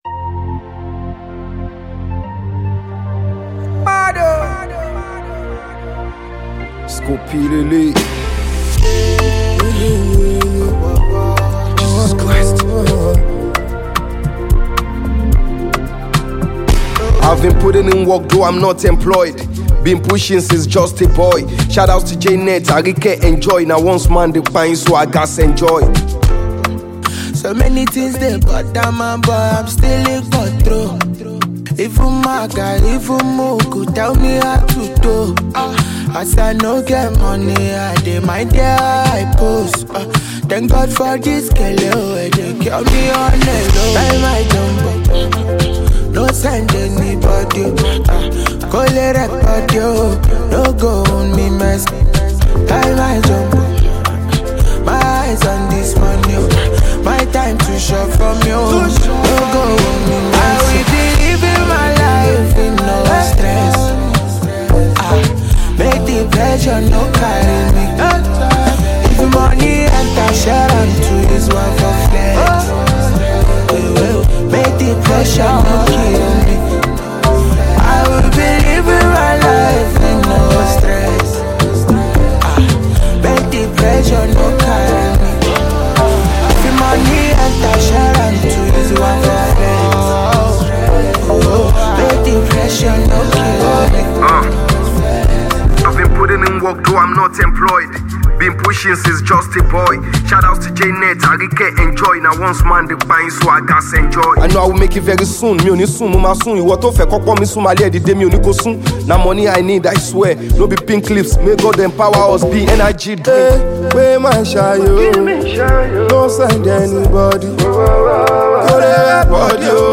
rapper
blend elements of Pop and Rap
With its infectious beats and catchy hooks